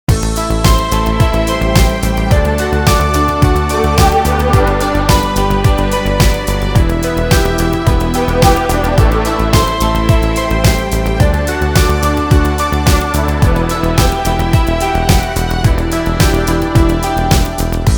• Качество: 320, Stereo
поп
мелодичные
Electronic
спокойные
без слов
клавишные
Приятная музыка без слов